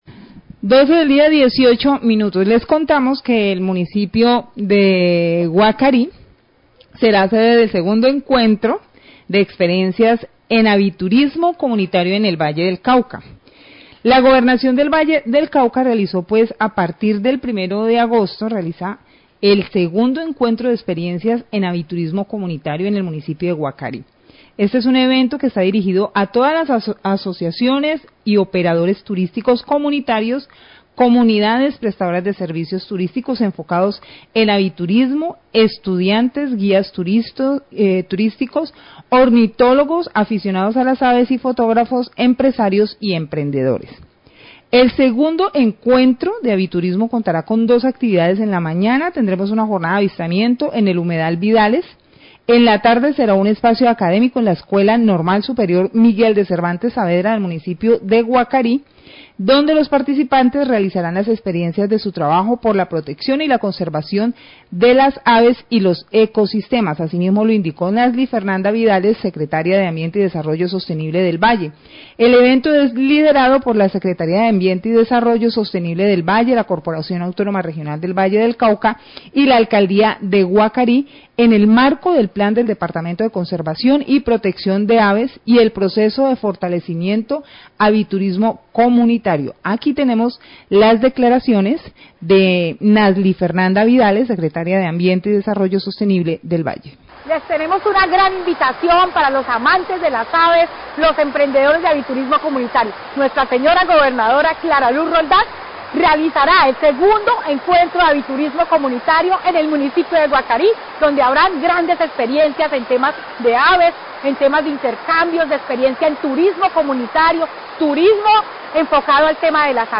Radio
El departamento del Valle del Cauca será epicentro del II Encuentro de Experiencias en Aviturismo Comunitario en el municipio de Guacarí. La Secretaria de Ambiente del Valle, Nasly F. Vidales, hace una invitación a  los amantes de las aves para disfrutar este evento.